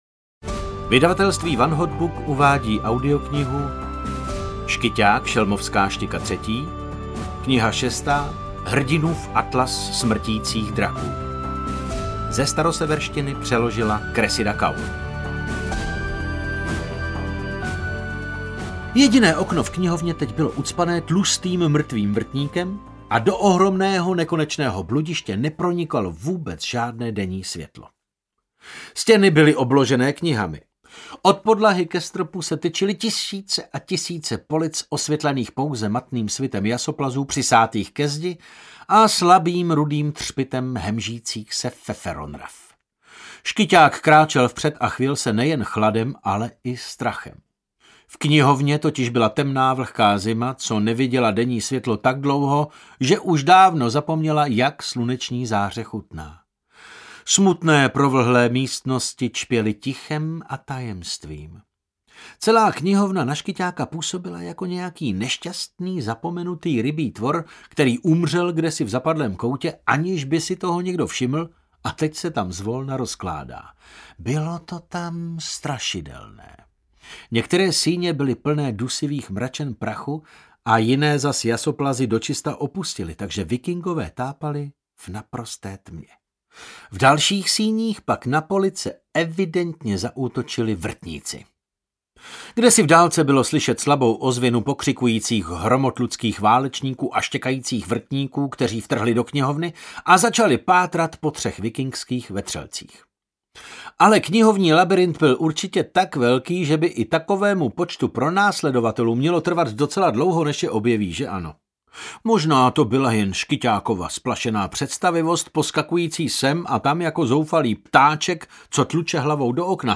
Hrdinův atlas smrtících draků audiokniha
Ukázka z knihy
• InterpretDavid Novotný